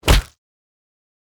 Punch Impact (Flesh) 4.wav